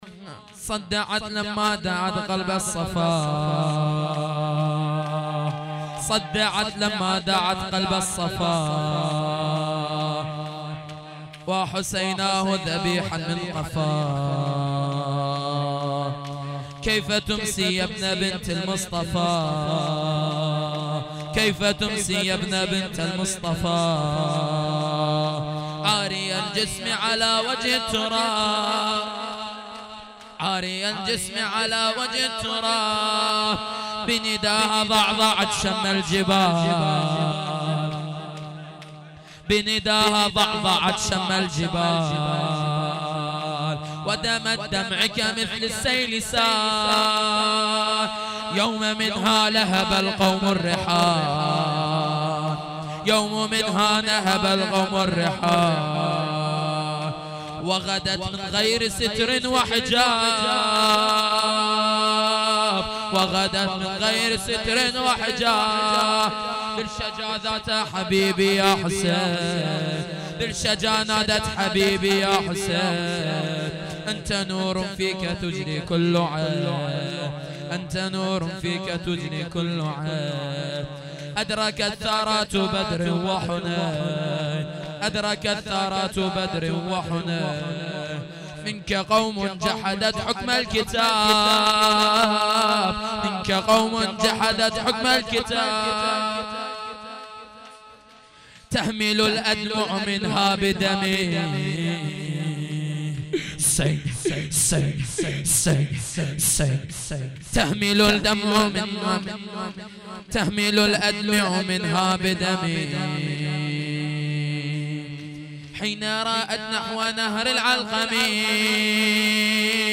heiat-levaolabbasshab-shahadat-hazrat-roghaieh-shor-arabi-farsi.mp3